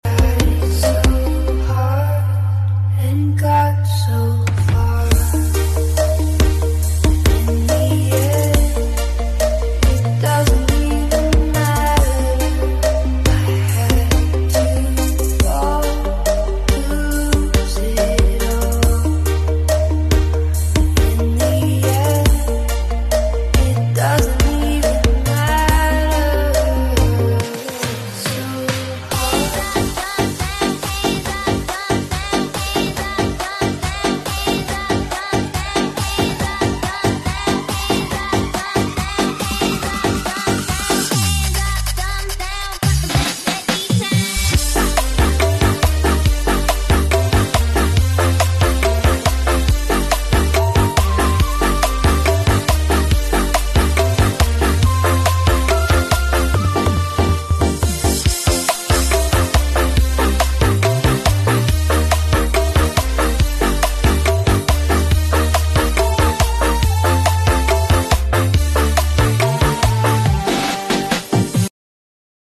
Live perfom